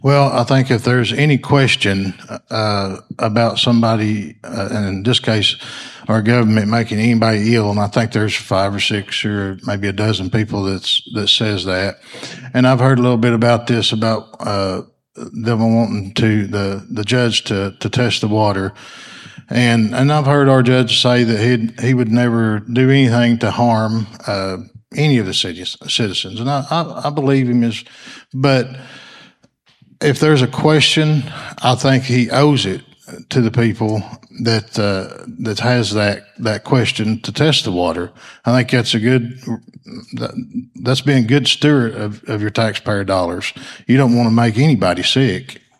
The public forum held at the Food Bank of North Central Arkansas saw County Judge Kevin Litty face challenger Eric Payne who currently serves as Justice of the Peace for District 11.